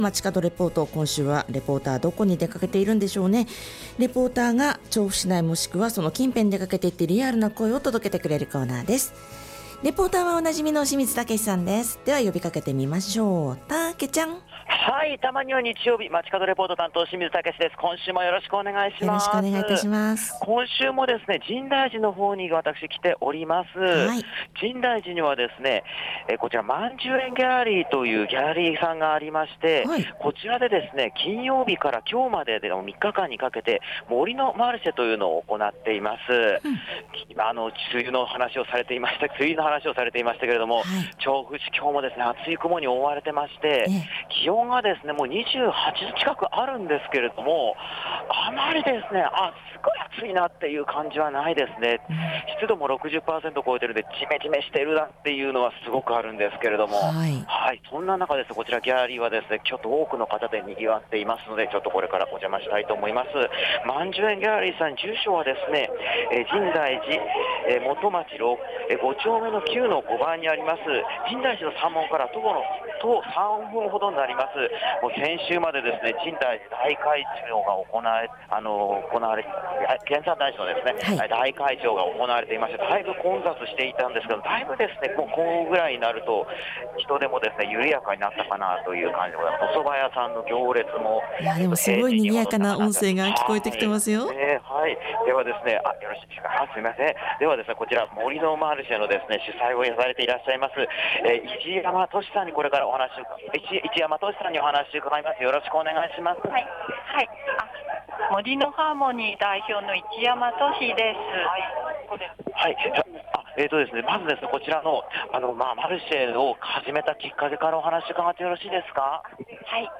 各地の梅雨入り情報がはS稀る中お届けした街角レポートは、深大寺周辺、深大通り沿いにある『曼珠苑ギャラリー』で開催中の 「杜のマルシェ」からのレポートです！